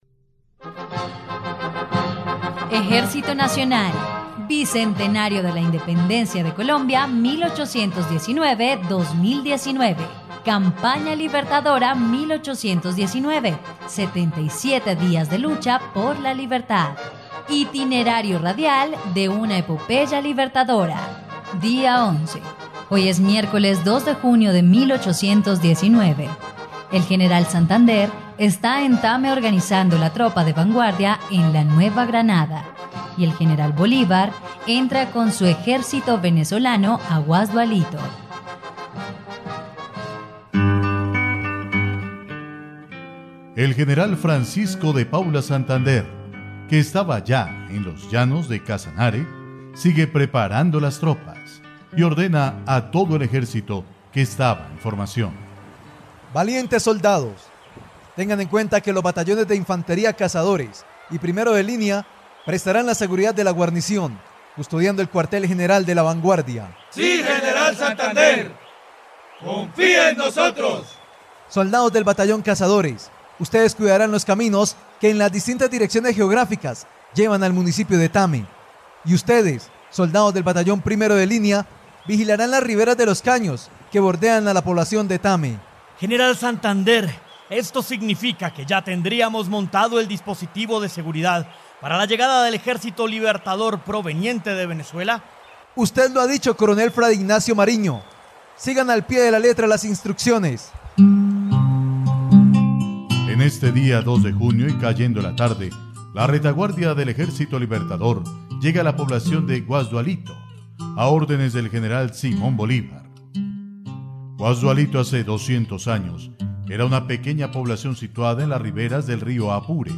dia_11_radionovela_campana_libertadora.mp3